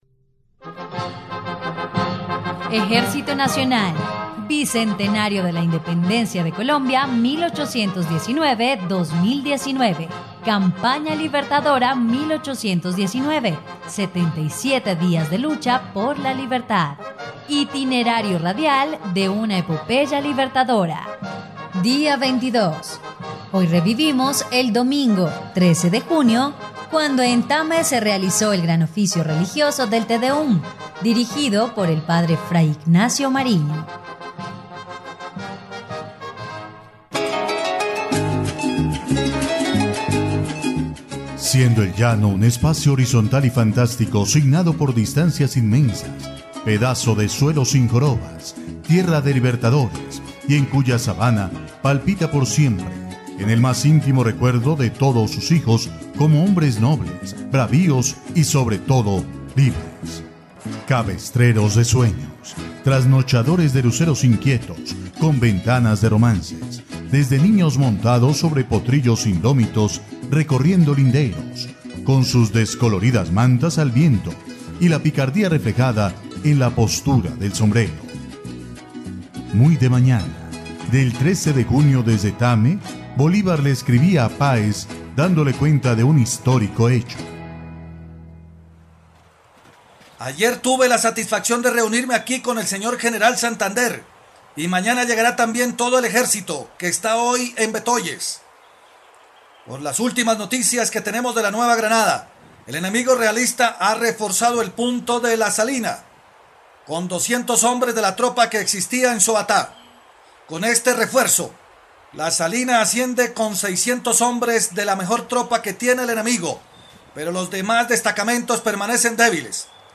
dia_22_radionovela_campana_libertadora.mp3